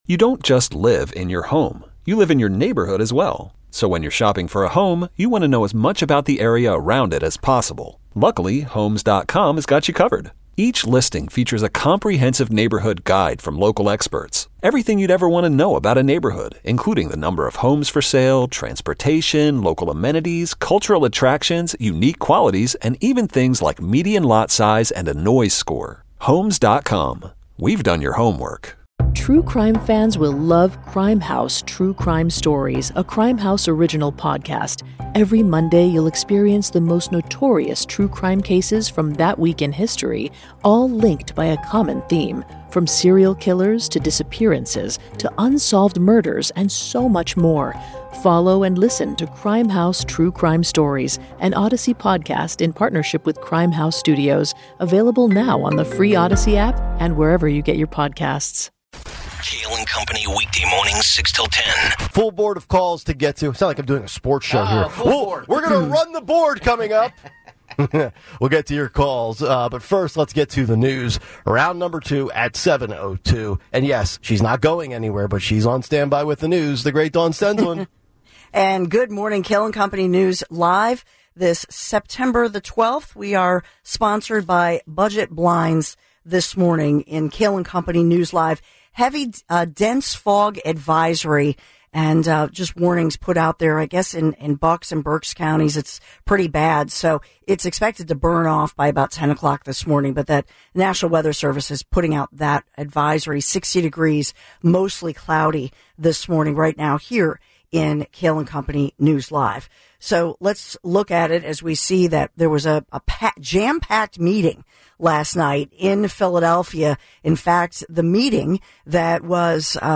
called in regarding Adderall conversation, Trump cutting taxes on OT